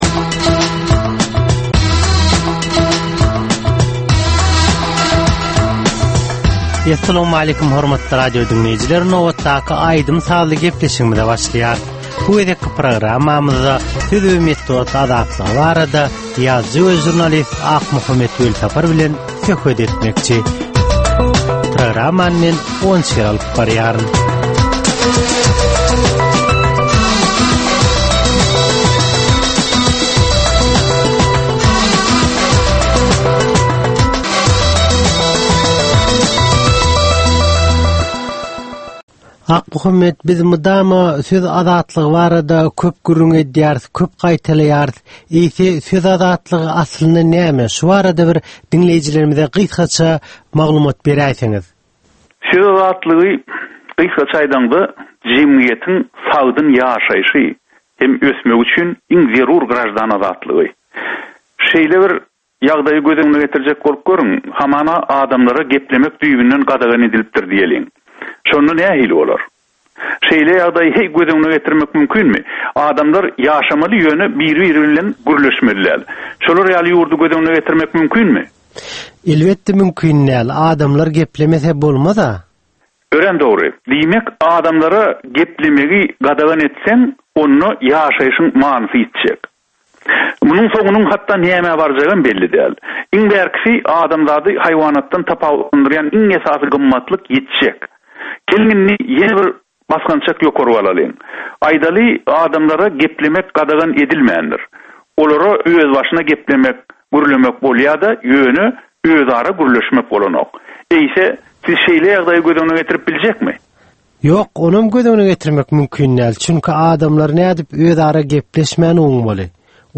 Türkmeniň käbir aktual meseleleri barada 30 minutlyk sazly-informasion programma.